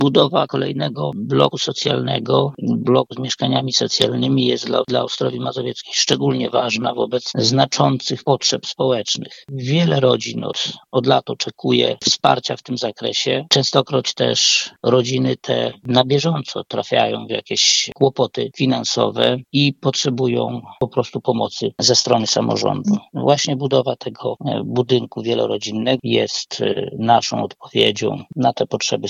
Burmistrz Ostrowi Mazowieckiej, Jerzy Bauer mówi, że ta inwestycja jest bardzo ważna dla mieszkańców.